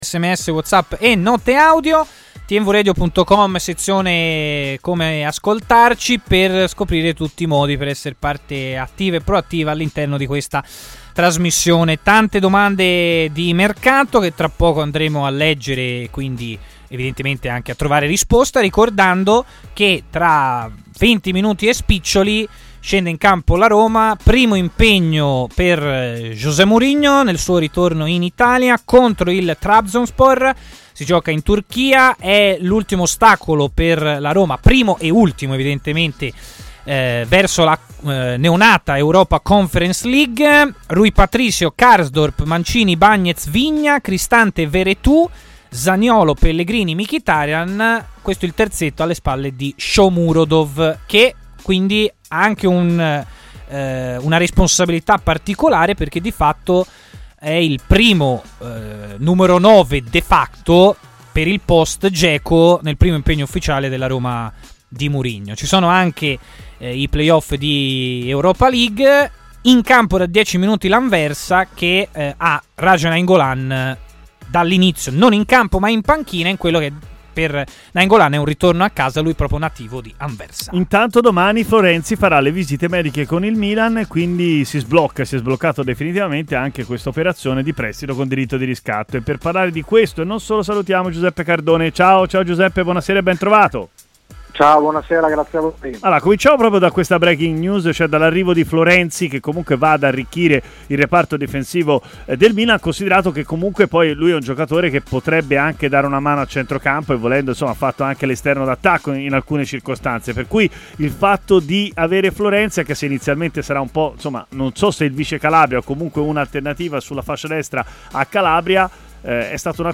ha così parlato in diretta a TMW Radio